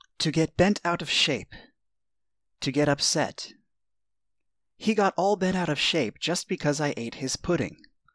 ネイティブによる発音は下記のリンクをクリックしてください。